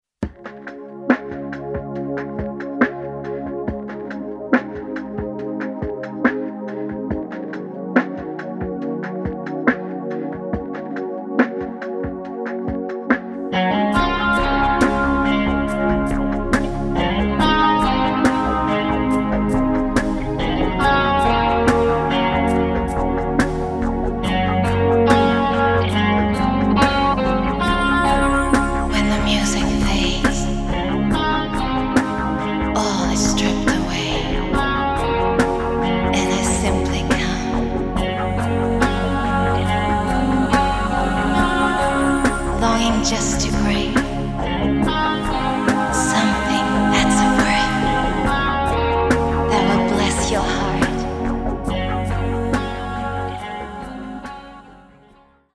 The intro guitar figure is the S-500.